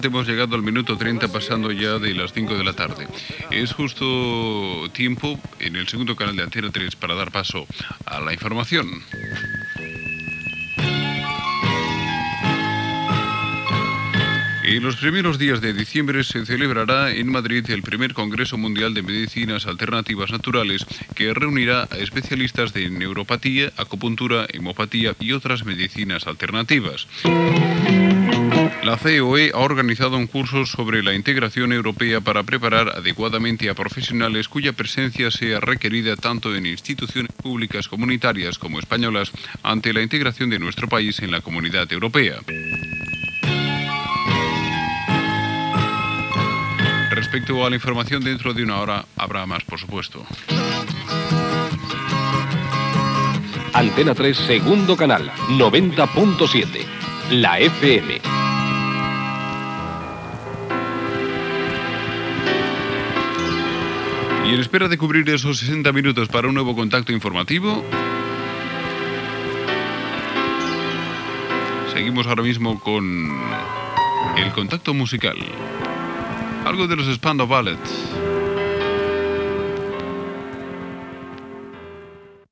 Identificació, servei informatiu: primer Congreso de Medicinas Alternativas Naturales, curs de la CEOE sobre la integració europea.
Informatiu
FM